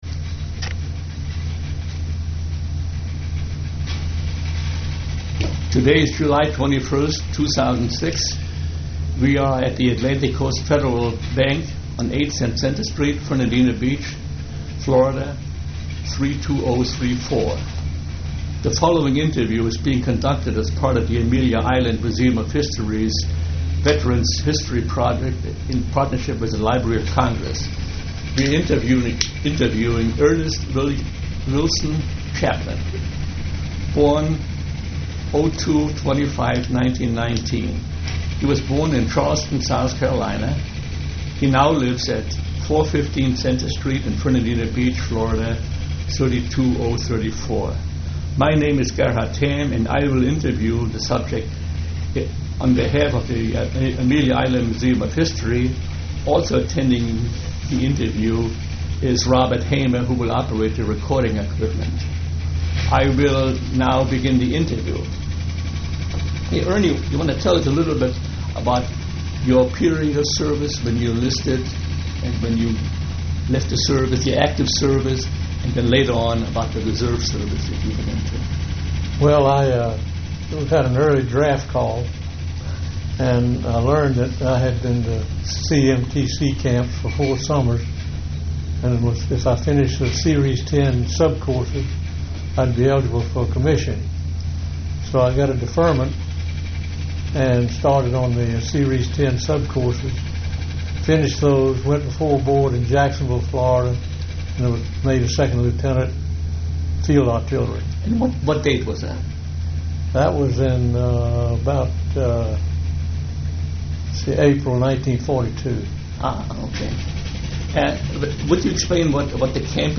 Click Here to play the Oral History Recording.